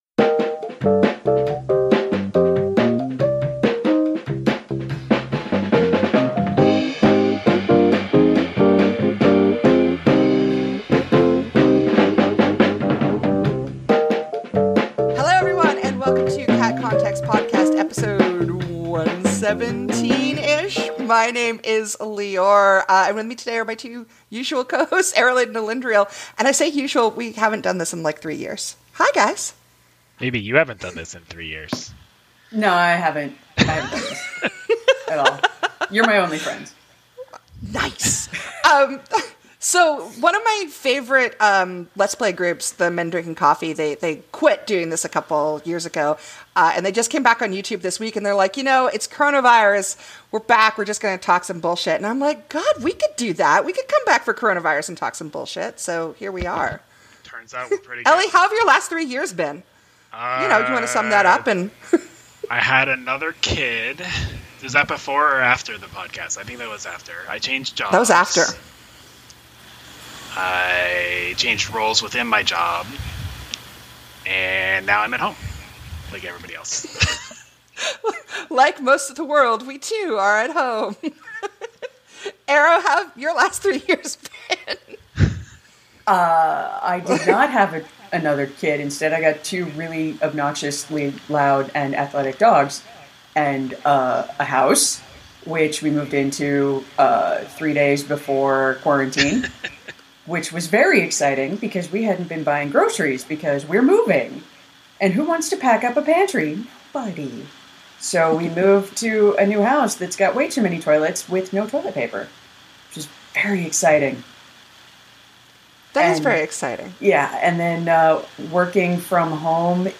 It was effectively three friends, hanging out on voice chat and talking about a bunch of loose topics for roughly an hour.